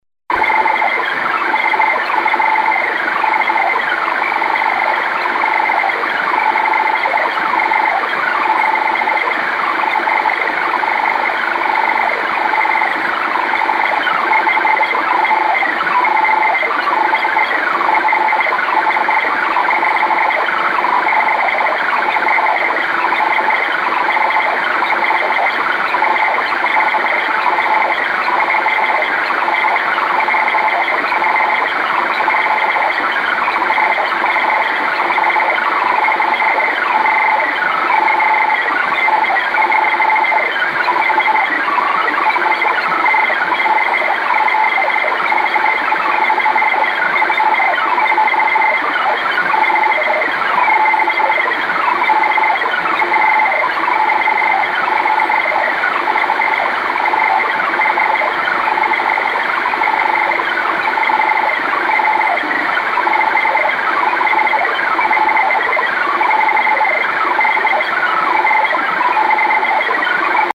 Начало » Записи » Радиоcигналы на опознание и анализ
DNA-similar signal (receive in SSB)
dna-similar_signal_receive_in_ssb.mp3